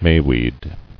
[may·weed]